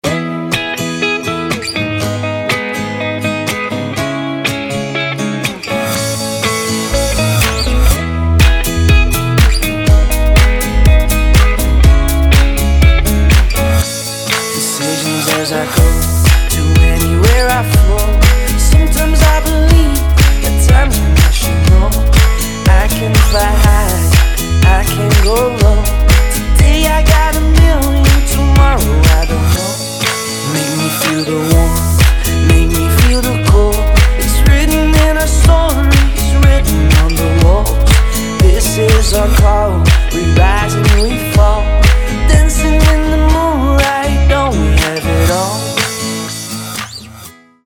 • Качество: 320, Stereo
deep house
Acoustic
Акустическая гитара и Дип-Хаус аранжировка